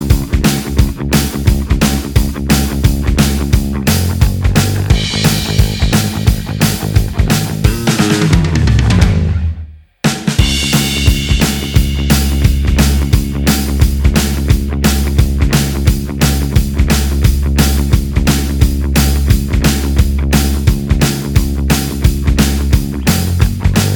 Minus All Guitars Rock 4:04 Buy £1.50